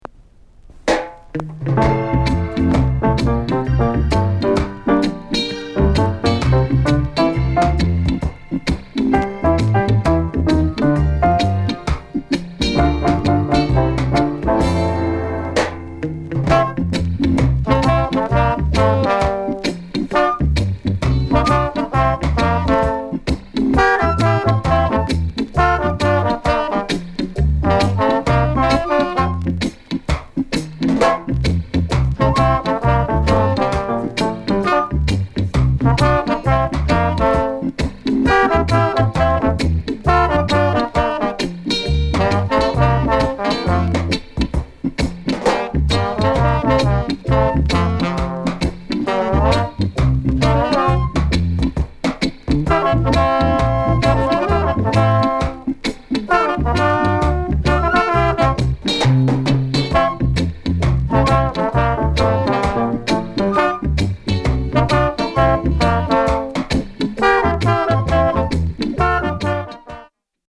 INST VERSION